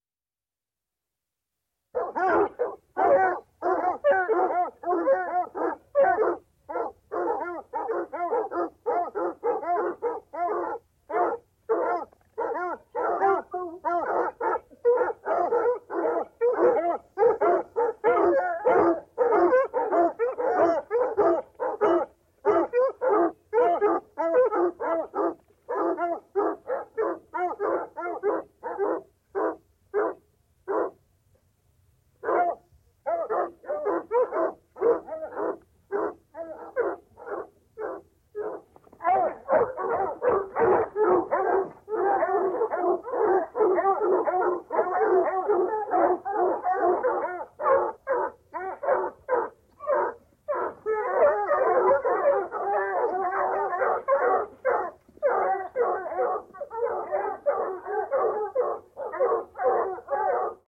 Звуки охоты
Голоса охотничьих собак в промысле